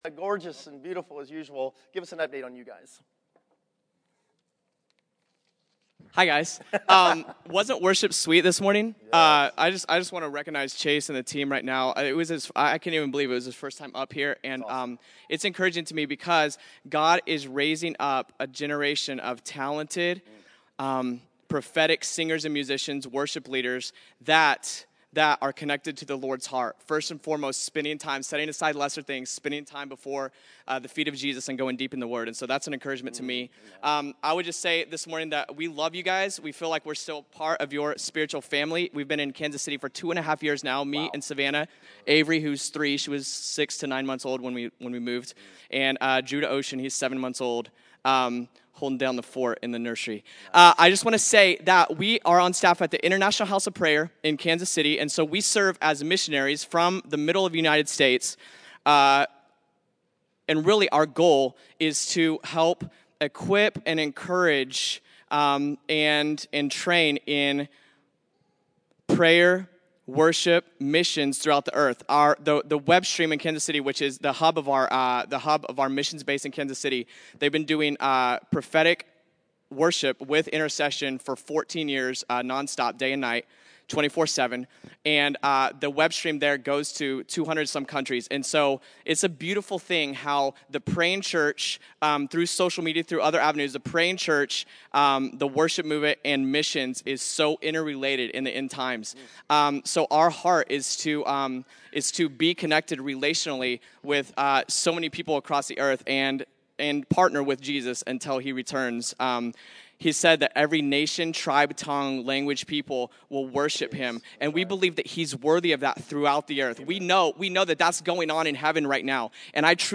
Category: Testimonies      |      Location: El Dorado